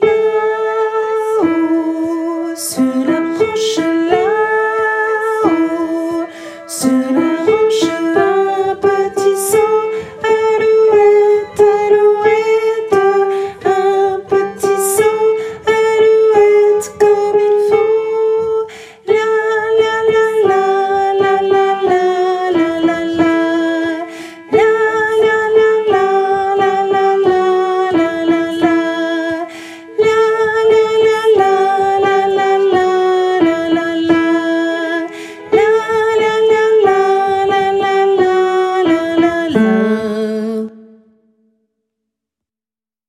- Œuvre pour choeur à 4 voix mixtes (SATB)
- chanson populaire de Lorraine
MP3 versions chantées
Basse et autres voix en arrière-plan